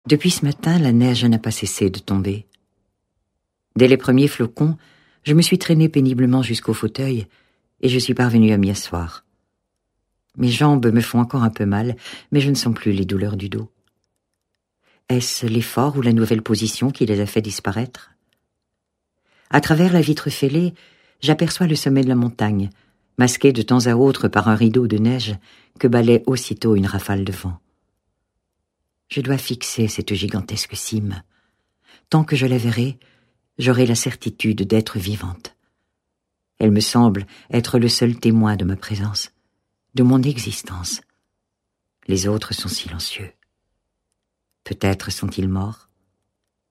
Elle lit ici neuf de ses contes ou nouvelles ayant un trait commun : la neige.